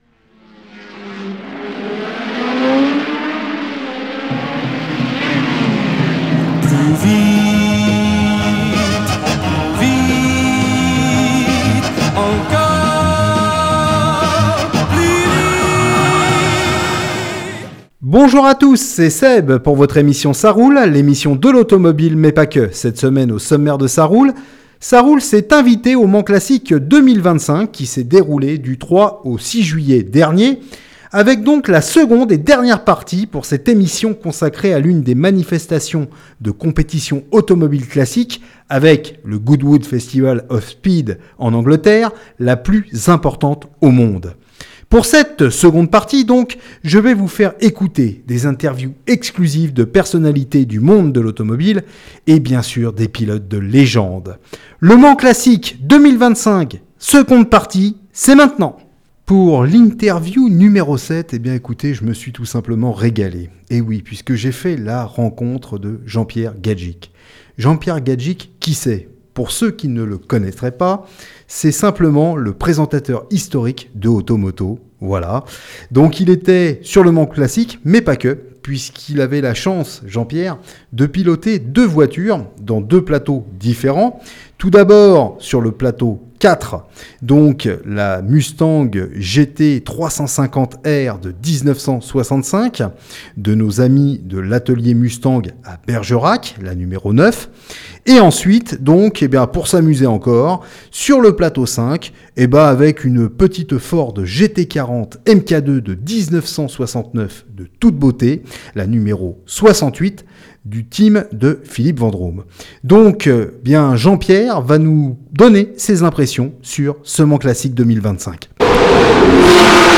Avec cette semaine au sommaire de "ÇA ROULE" la seconde partie du " MANS CLASSIC 2025 " auquel "ÇA ROULE" a eu la chance d'assister et d'interviewer pour cette seconde partie Jean-Pierre Gagick le présentateur phare de l'émission AutoMoto, ou encore Le pilote Français Jean-Pierre Malcher Champion de France à l'âge d'or du DTM français, ensuite un grand pilote français de Rallye-Raid que se soit en moto ou en voiture en la personne de Cyril Despres et puis une interview exceptionnelle au micro de Liberté FM avec un pilote français ex-pilote de formule 1 dans les années 80-90 au patronyme connu de toutes et tous.